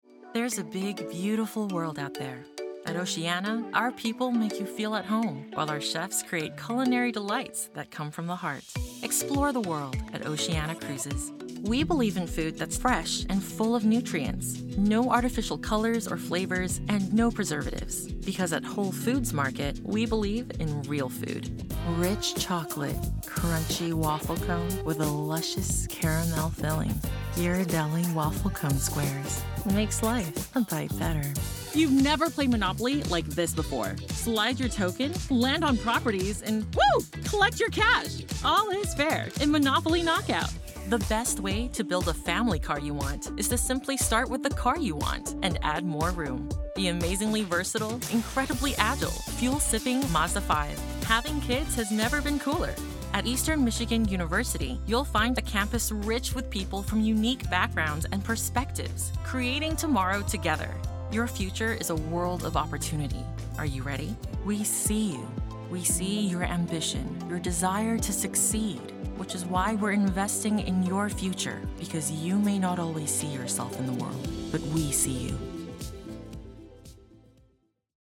Voiceover Demos
Commercial Demo